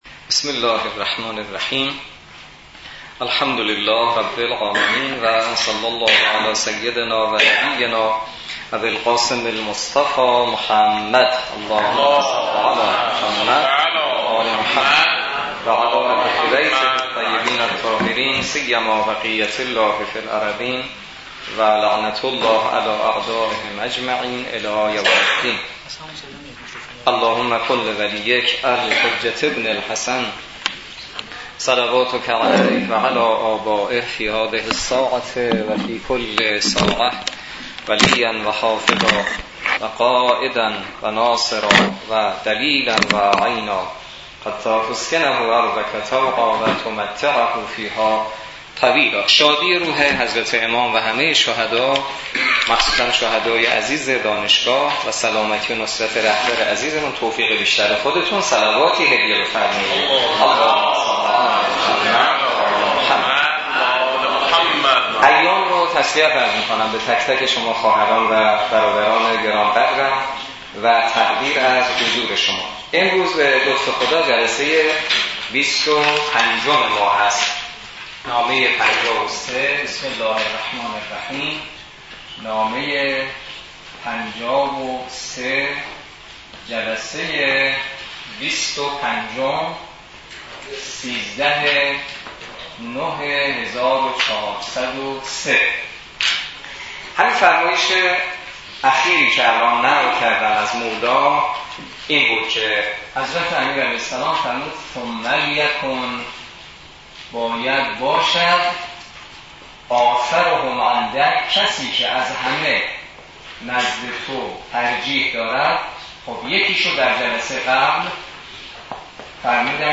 برگزاری بیست و پنجمین جلسه مباحثه نامه ۵۳ نهج البلاغه توسط نماینده محترم ولی فقیه و در دانشگاه کاشان
بیست و پنجمین جلسه مباحثه نامه ۵۳ نهج البلاغه توسط حجت‌الاسلام والمسلمین حسینی نماینده محترم ولی فقیه و امام جمعه کاشان در دانشگاه کاشان برگزار...